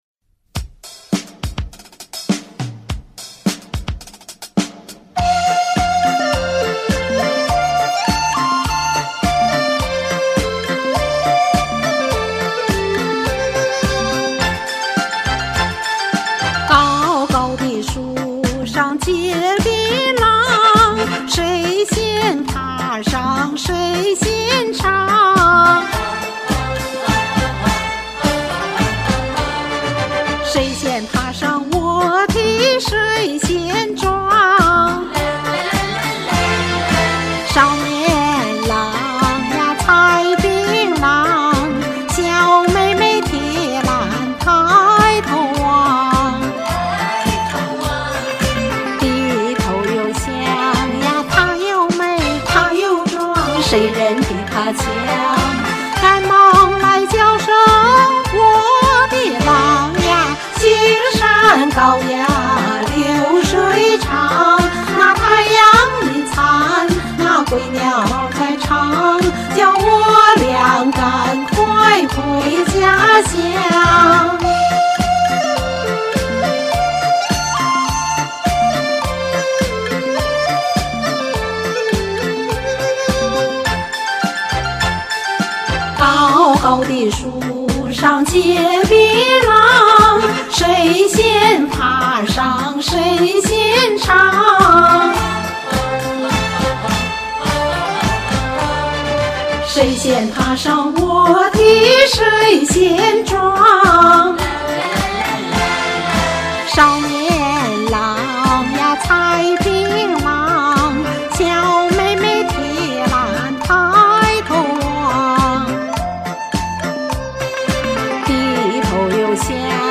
湖南民歌
這首歌唱的太好聽了，活潑生動！